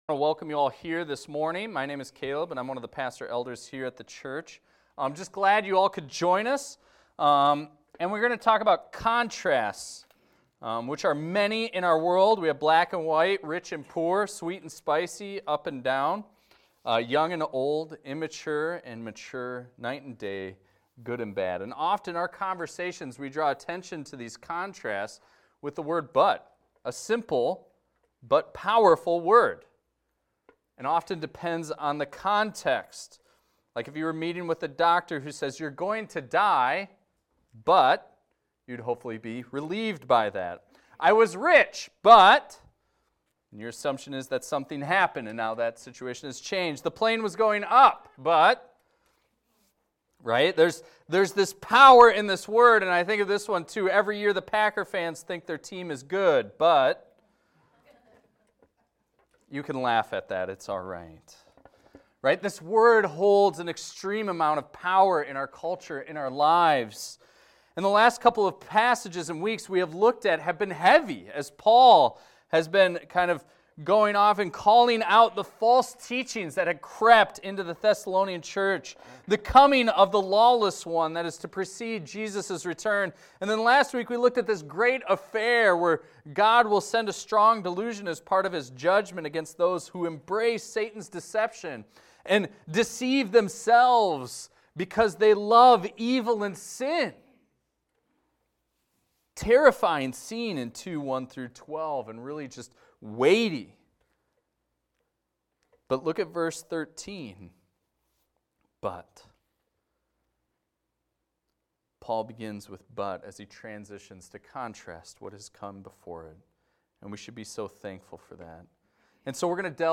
This is a recording of a sermon titled, "Stand Firm."